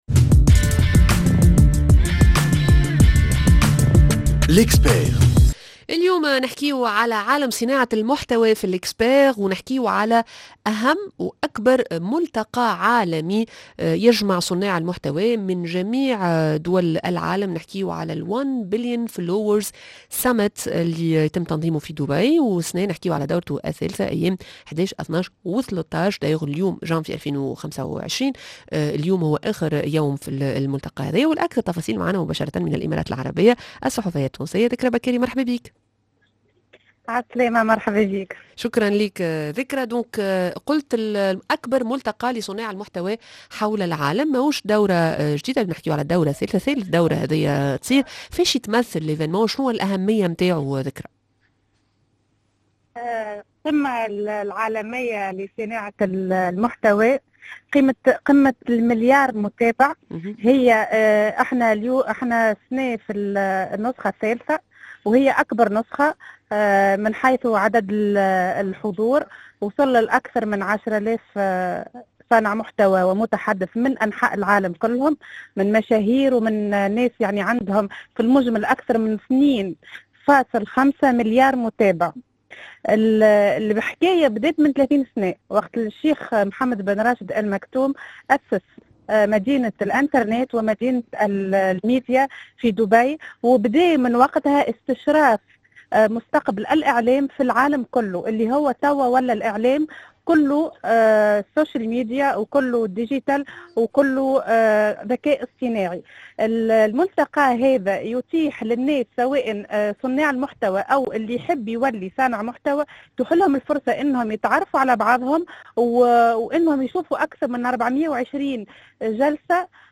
en direct de Dubaï